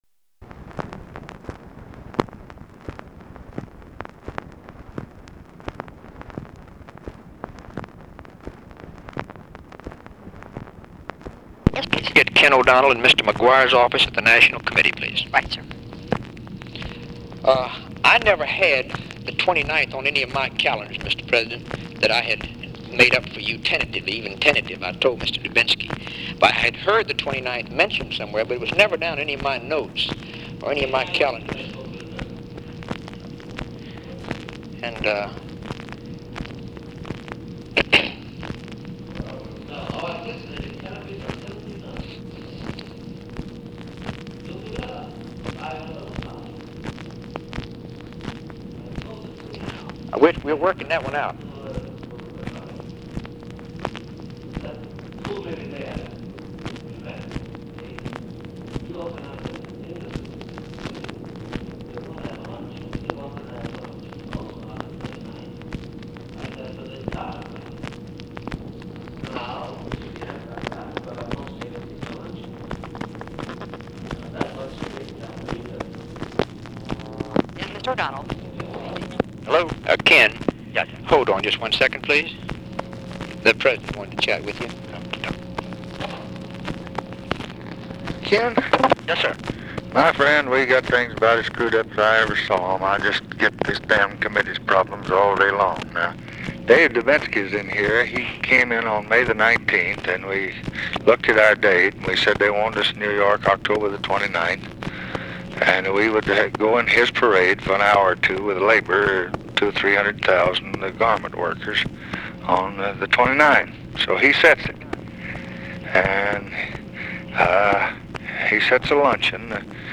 Conversation with KEN O'DONNELL, JACK VALENTI and OFFICE CONVERSATION, September 18, 1964
Secret White House Tapes | Lyndon B. Johnson Presidency